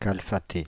Ääntäminen
Paris: IPA: [kal.fa.te]